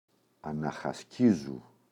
αναχασκίζω [anaxa’skizo]
αναχασκίζου.mp3